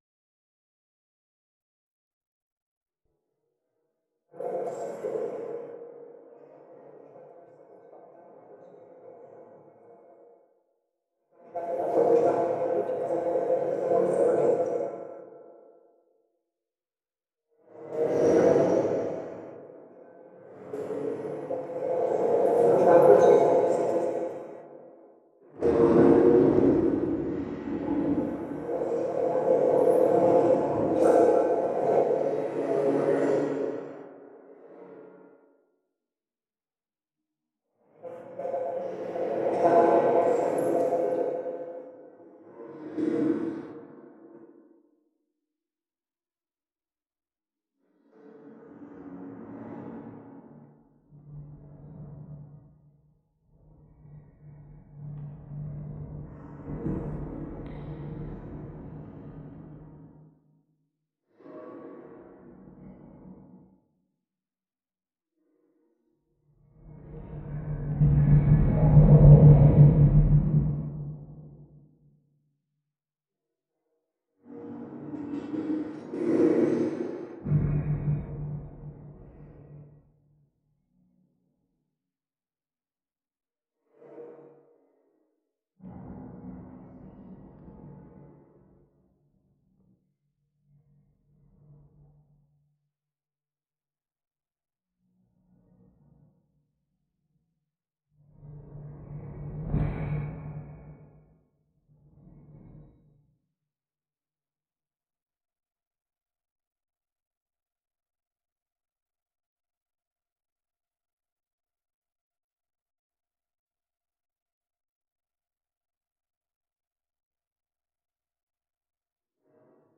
reimagining of Metra boarding platform sounds, Chicago.
Automation allows for a 'plug and play' generative approach.